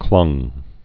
(klŭng)